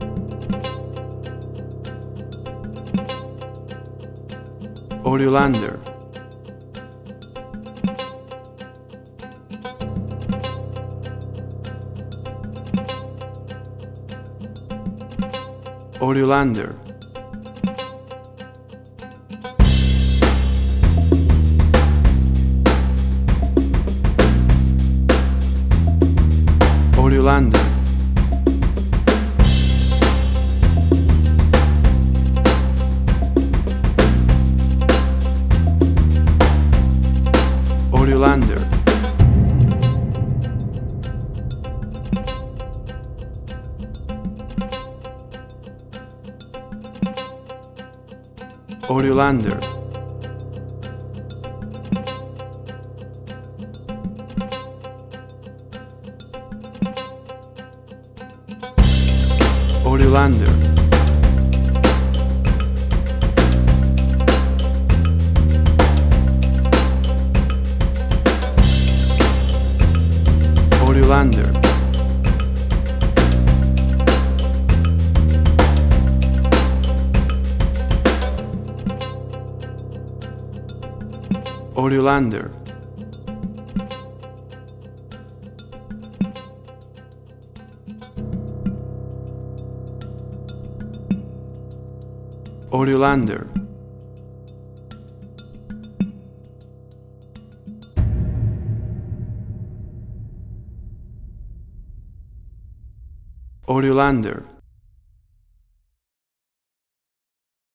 Suspense, Drama, Quirky, Emotional.
Tempo (BPM): 98